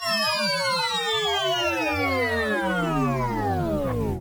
I was messing with the SCP-294 "out of range" sound, and created these two sounds.
falldown.ogg